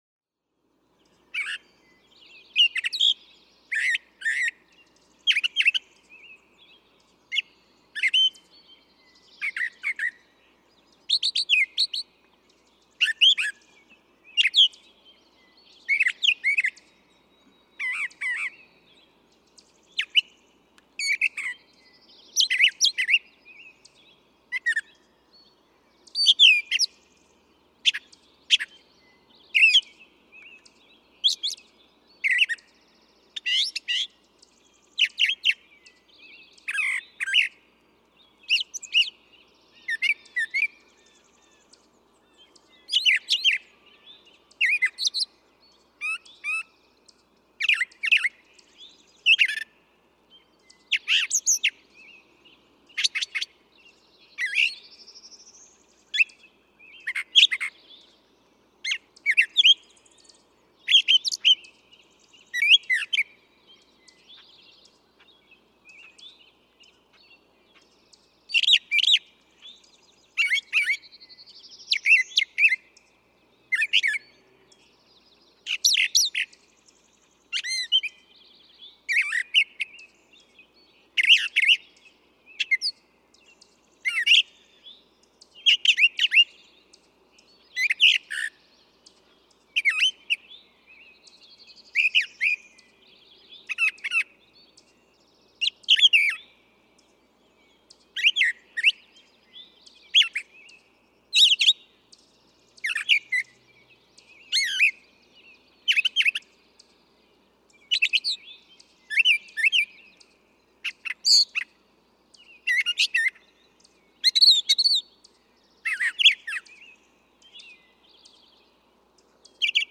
Theme and variations—brown thrasher
The theme of the brown thrasher is twoness, but he sings oh so many variations on that theme.
Montague Plains Wildlife Management Area, Montague, Massachusetts.
651_Brown_Thrasher.mp3